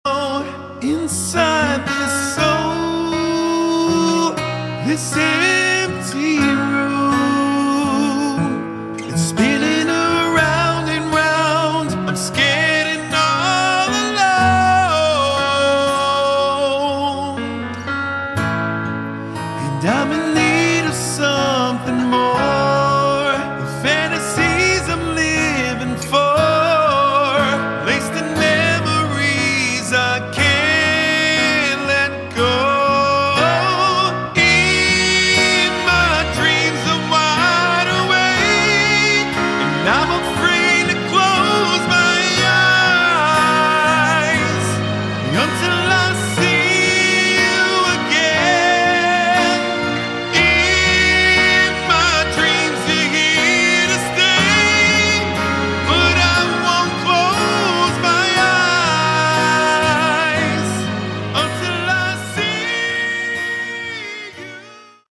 Category: Melodic Rock
lead guitars
drums